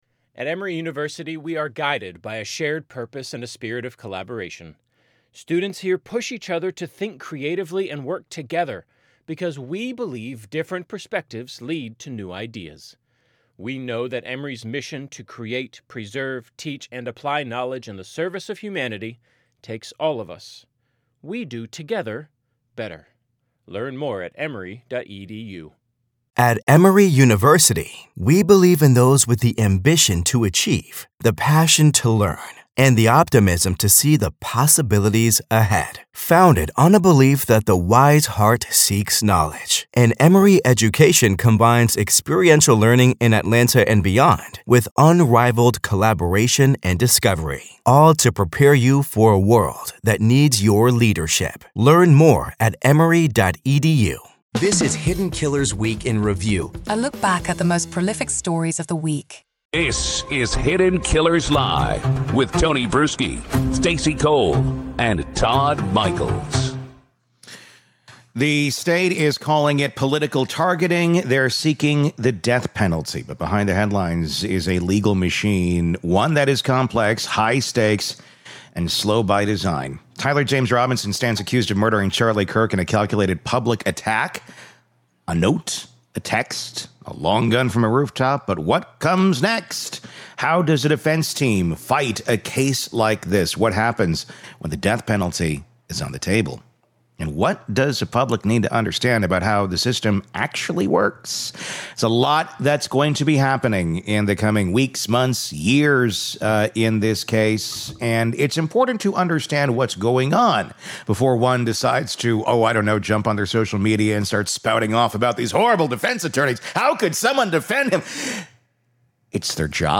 In this special longform segment, criminal defense attorney and former prosecutor